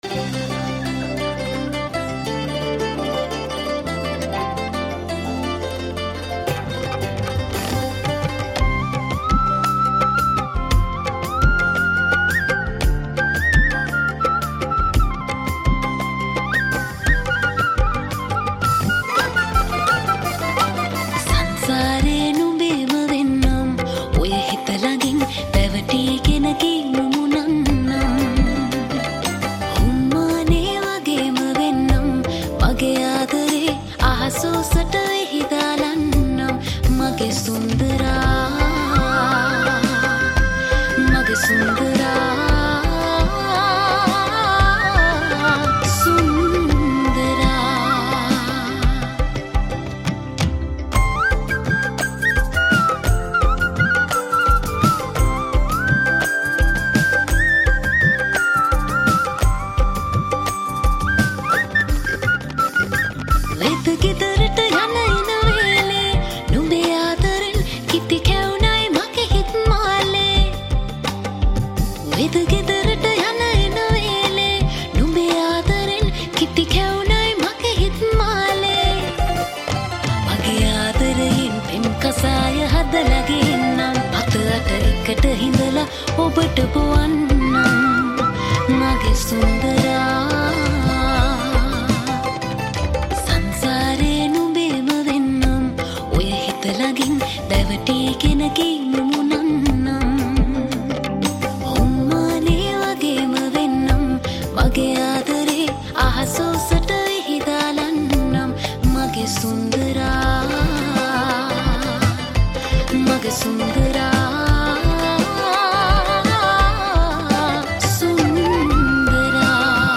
High quality Sri Lankan remix MP3 (3.3).
remix